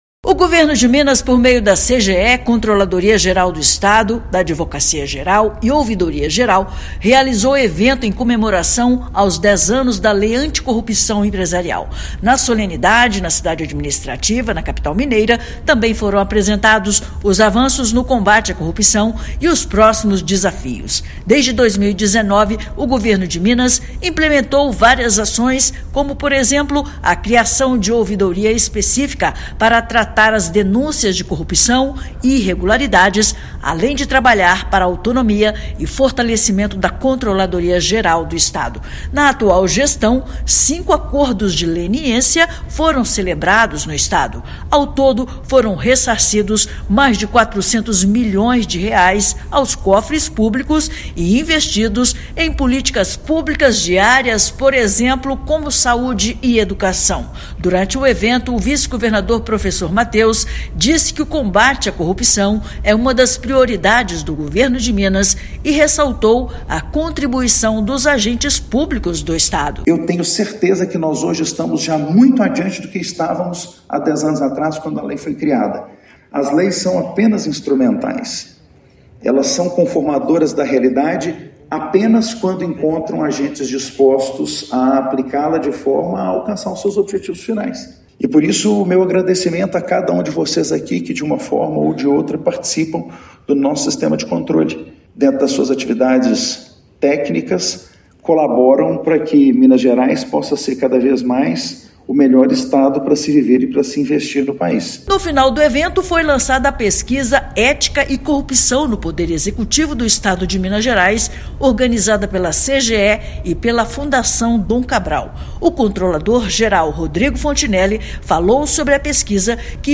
Agência Minas Gerais | [RÁDIO] Governo de Minas analisa cenários e avanços no aniversário de 10 anos da Lei Anticorrupção
Evento realizado pela CGE, AGE e OGE propôs reflexões sobre próximos desafios na regulamentação da legislação no Poder Executivo estadual e lançamento de pesquisa sobre corrupção na Administração Pública. Ouça matéria de rádio.